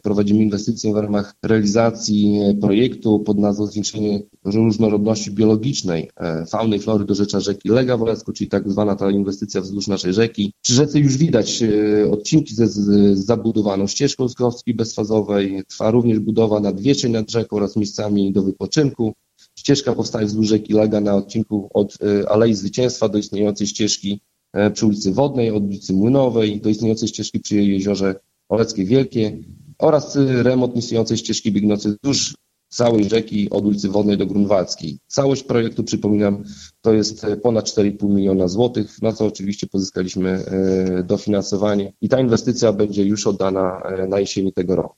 O szczegółach Karol Sobczak, burmistrz Olecka.